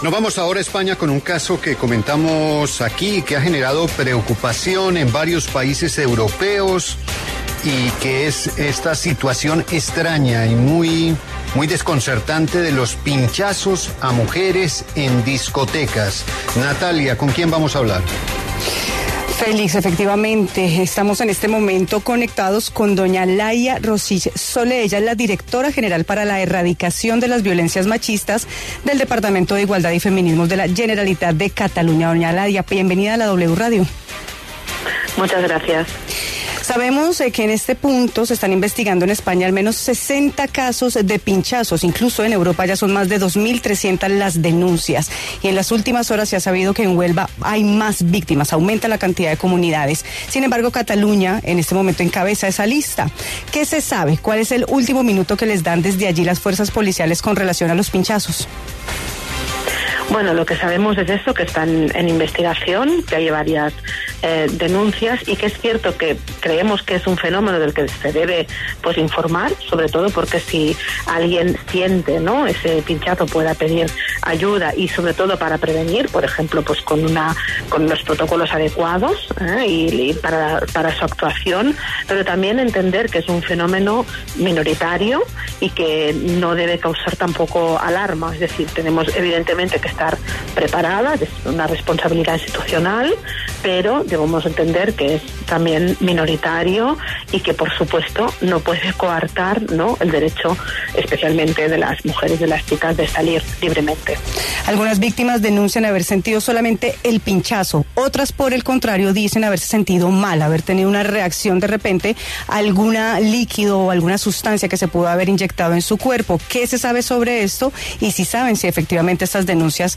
En el encabezado escuche la entrevista completa con Laia Rosich Solé, directora general para la Erradicación de las Violencias Machistas del Departamento de Igualdad y Feminismos de la Generalitat de Cataluña.